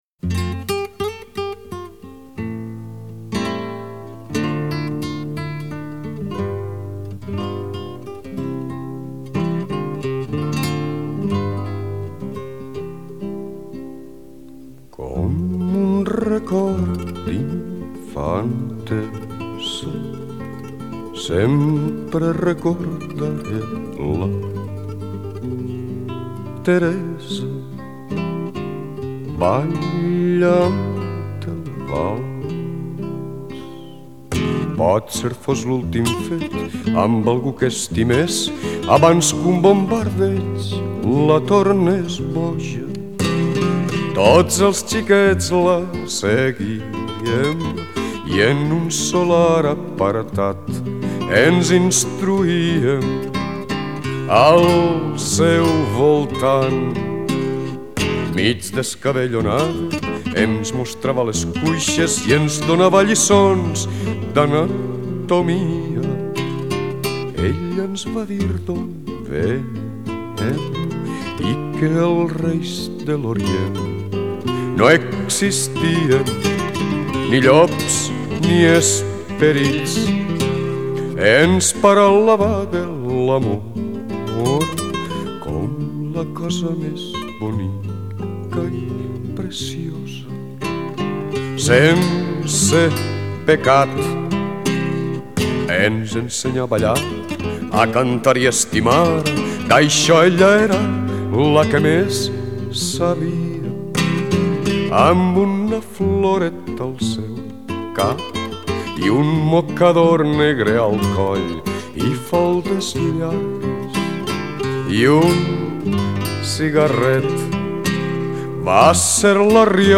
El valset francès